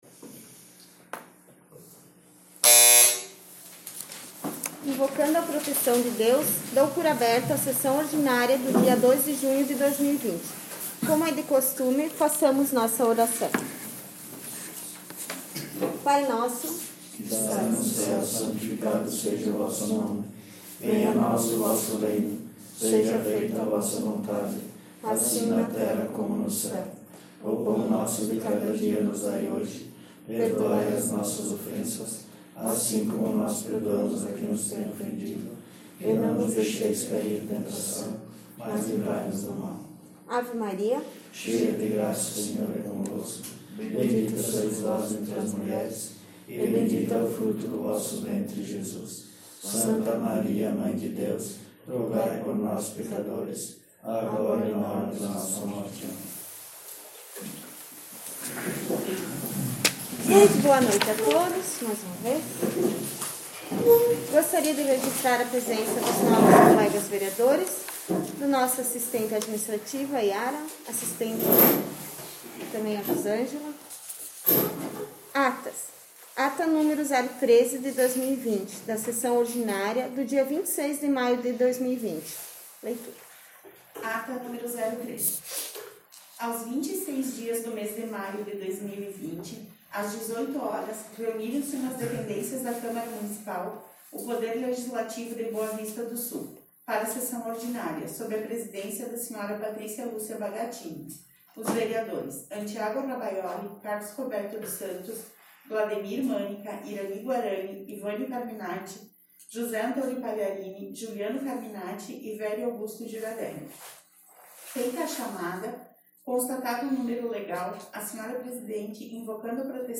Sessão Ordinária 02/06/2020